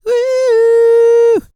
E-CROON 3043.wav